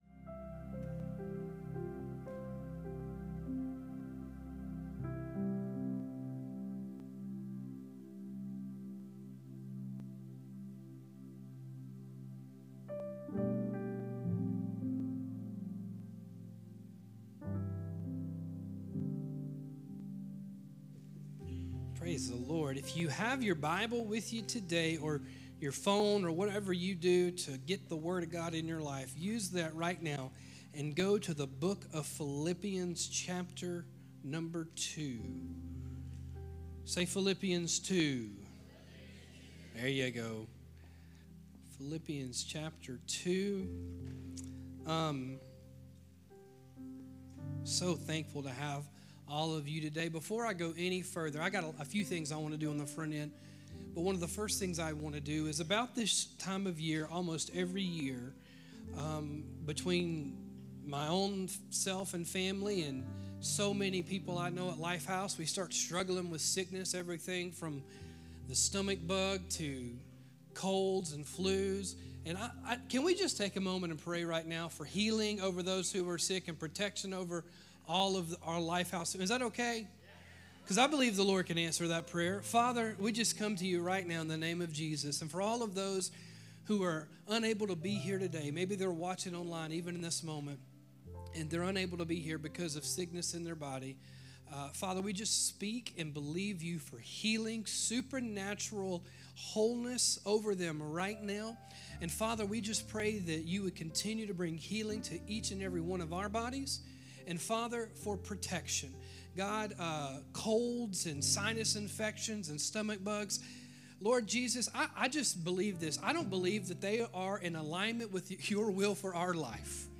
We hope you are encouraged in your walk with Jesus by this message.